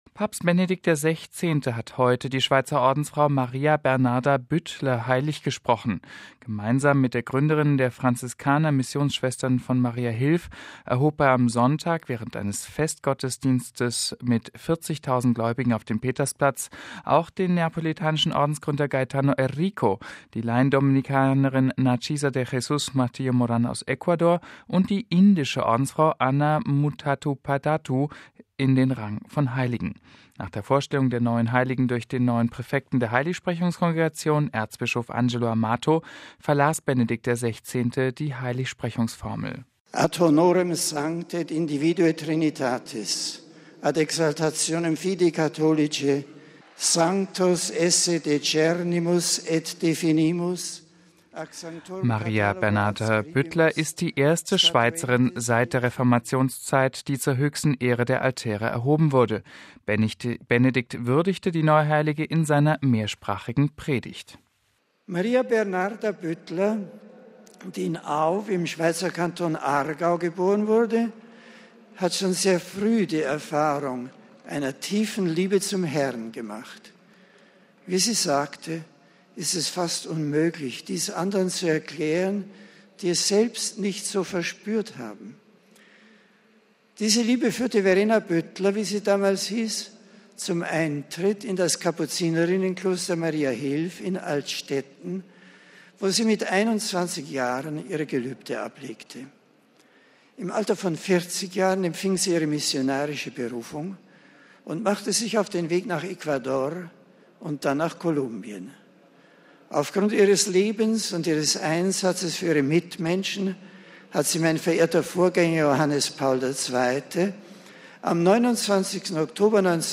Benedikt würdigte die neue Heilige in seiner mehrsprachigen Predigt:
An dem Heiligsprechungsgottesdienst auf dem Petersplatz nahmen zehntausende Gläubige teil.